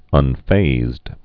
(ŭn-fāzd)